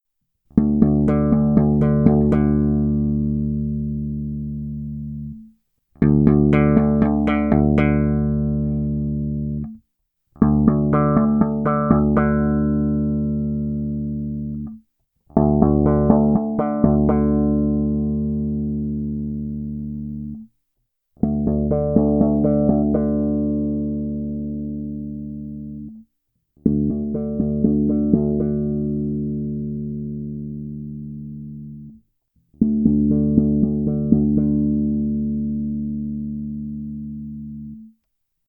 Mám zkušenost, že změny charakteru se projeví nejvíce u slapu, zvláště u trhaných tónů, takže jen velice rychlá ukázka od pasívního režimu přes filtr 1 po 6.